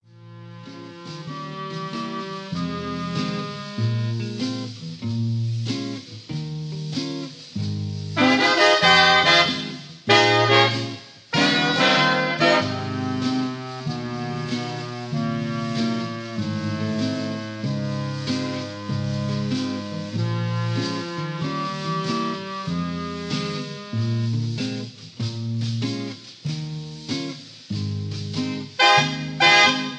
karaoke mp3 tracks